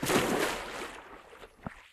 water_splash03.wav